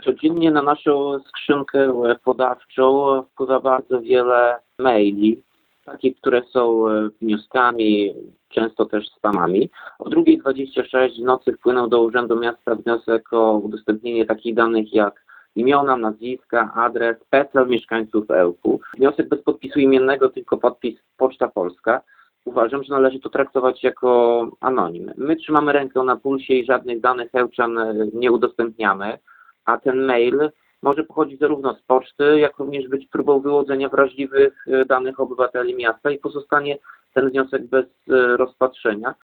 – Przyszedł na skrzynkę podawczą o 2:26 w nocy 23 kwietnia – mówi włodarz miasta.